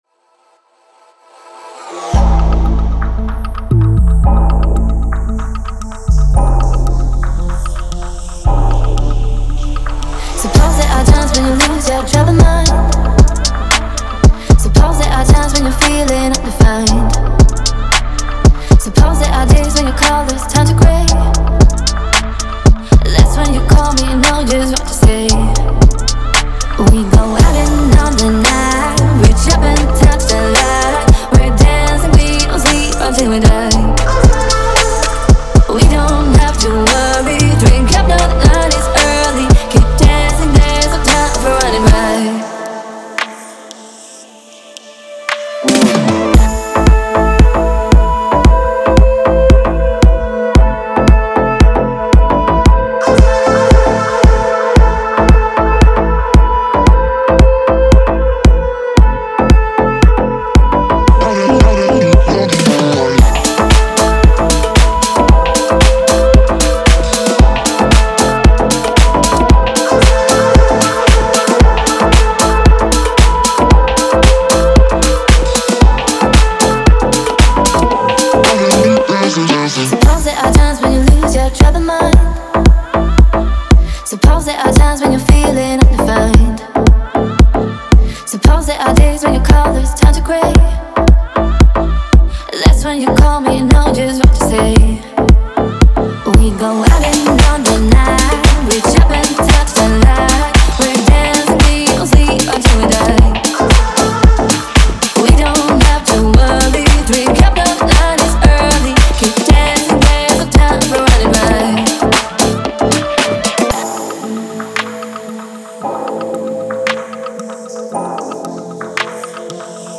это энергичная композиция в жанре техно